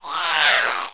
swoop1.wav